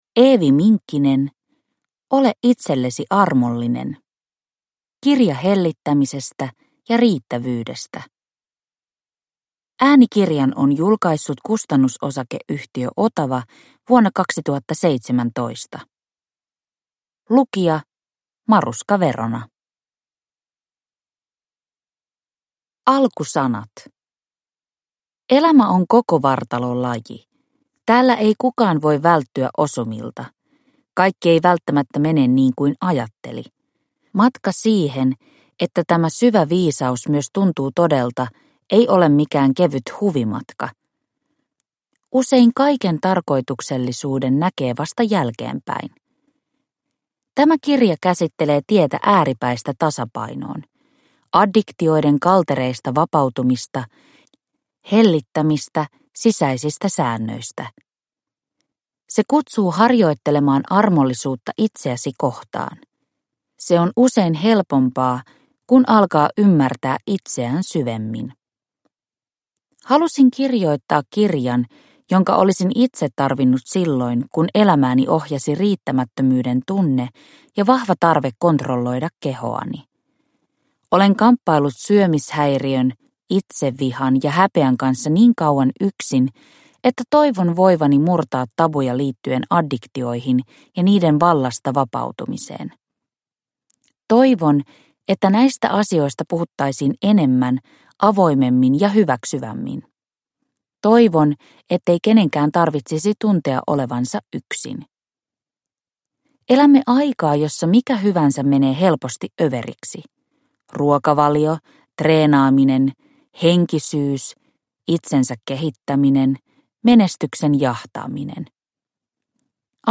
Ole itsellesi armollinen – Ljudbok – Laddas ner